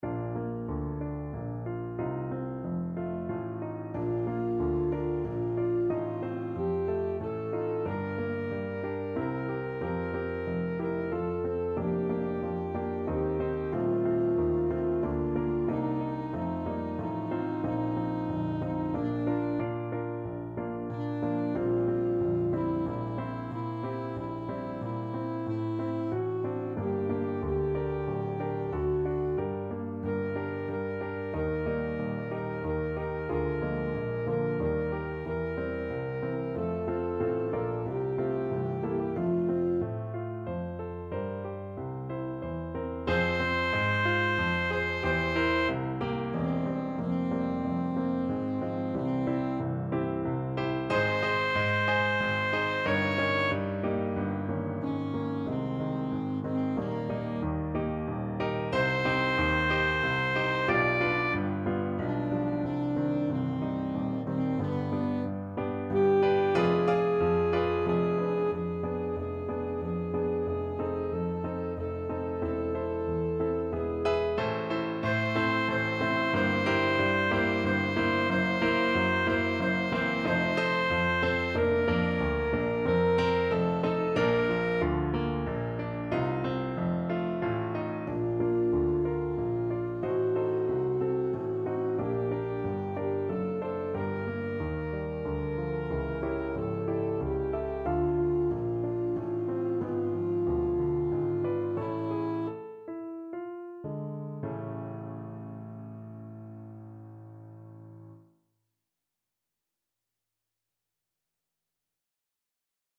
Hostias Alto Saxophone version
Play (or use space bar on your keyboard) Pause Music Playalong - Piano Accompaniment Playalong Band Accompaniment not yet available reset tempo print settings full screen
3/4 (View more 3/4 Music)
Bb major (Sounding Pitch) G major (Alto Saxophone in Eb) (View more Bb major Music for Saxophone )
~ = 92 Larghetto
mozart_requiem_hostias_ASAX.mp3